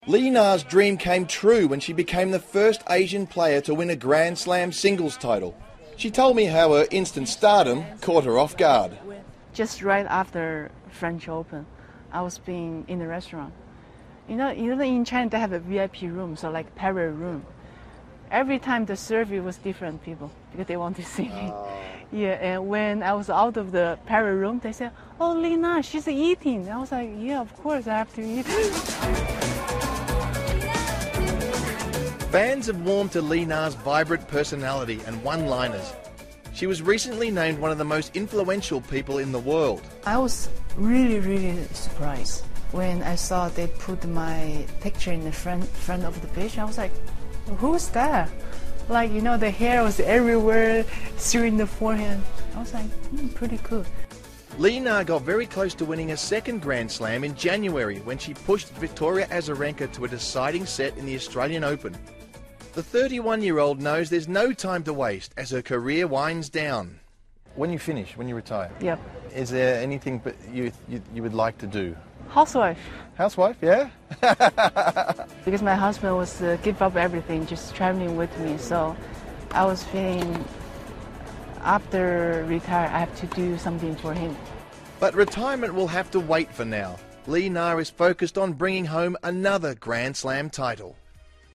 访谈录 2013-05-30&06-01 中国网球一姐李娜专访 听力文件下载—在线英语听力室